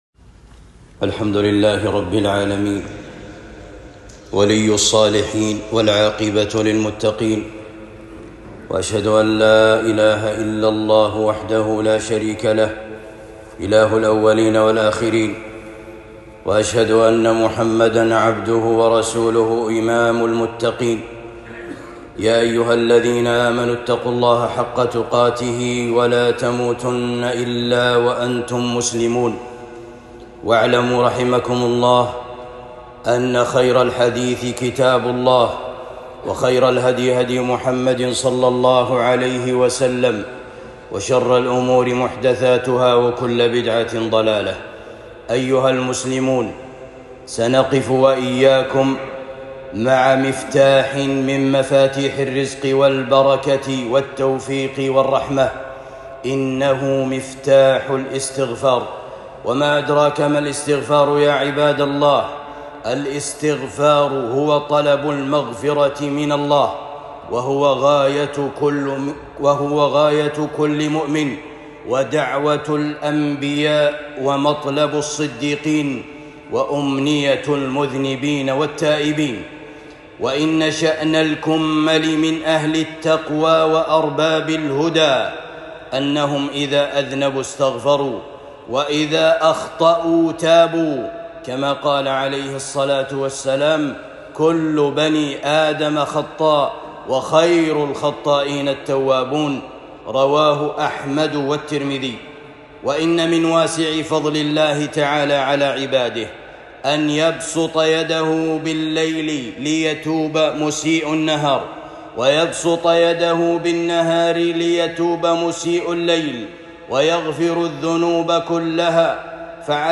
الخطب